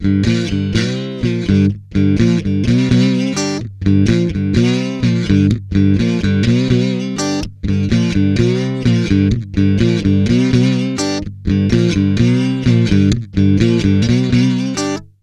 Index of /90_sSampleCDs/Best Service ProSamples vol.17 - Guitar Licks [AKAI] 1CD/Partition D/VOLUME 007